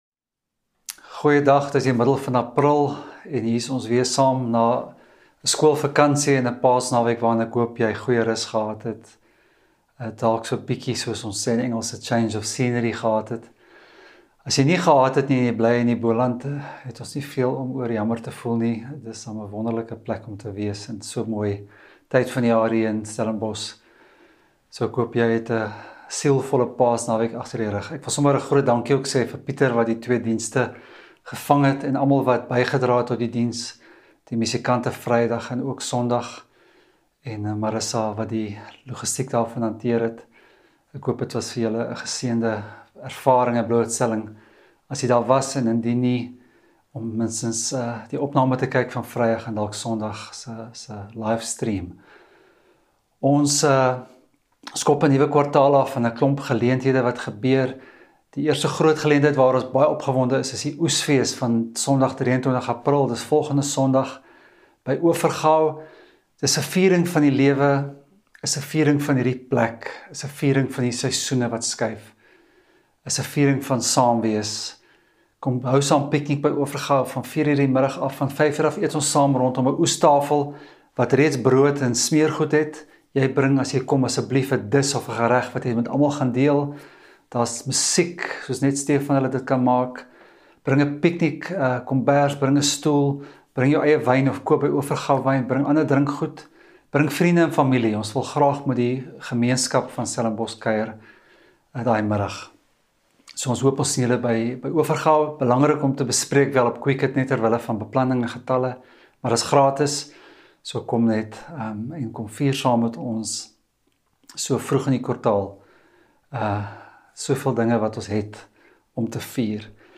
Stellenbosch Gemeente Preke 16 April 2023 || Storielyn - Wat is die lewe nou weer?